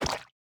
ink_sac3.ogg